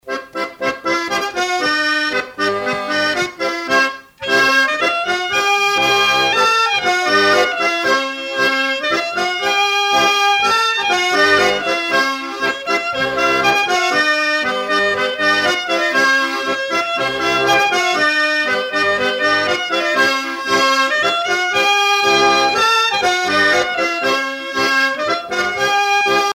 danse : violette
Pièce musicale éditée